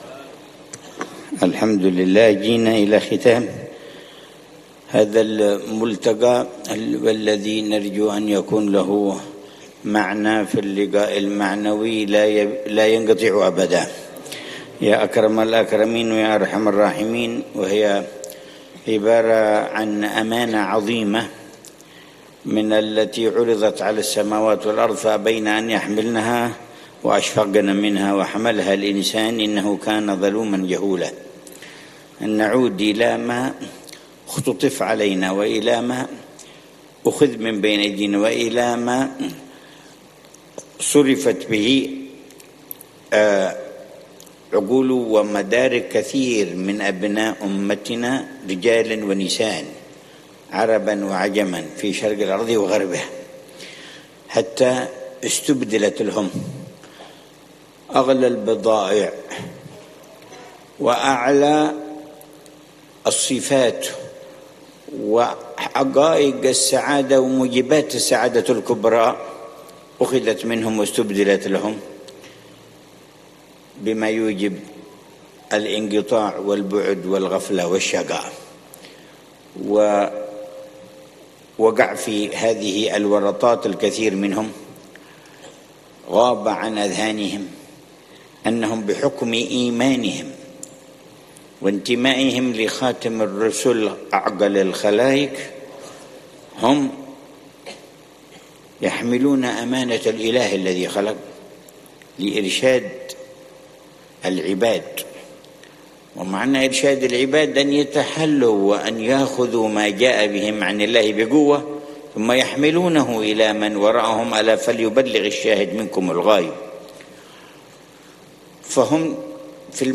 كلمة في الجلسة الختامية لملتقى الدعاة السنوي (19)
كلمة العلامة الحبيب عمر بن محمد بن حفيظ في الجلسة الختامية لملتقى الدعاة السنوي (التاسع عشر)، بعنوان: (حقيقة العقل وحُسن استعماله ومهمته في العدل بين الشهوات والغضب والحُكم في المحسوسات الماديّة والغيبيّات المعنوية وأثر كل ذلك على اتجاه ووجهة الافراد والجماعات والشعوب والدول)